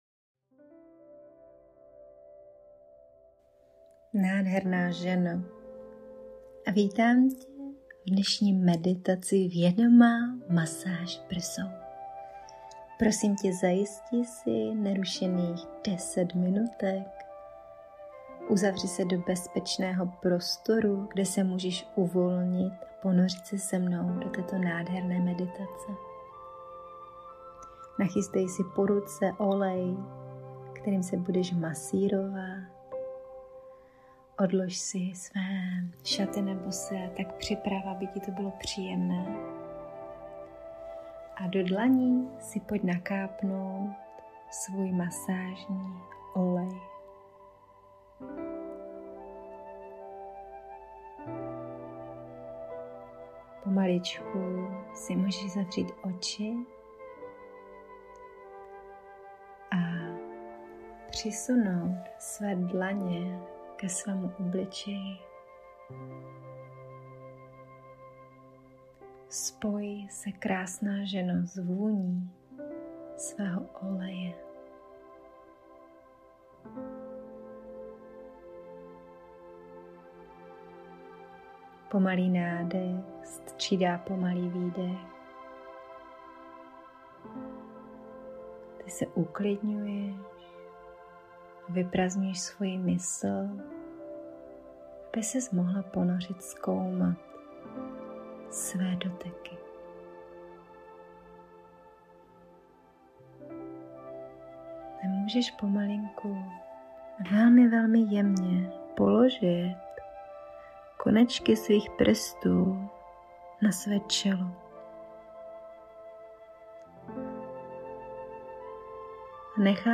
Meditace ke stazeni
Meditace-Vedoma-masaz-prsou.mp3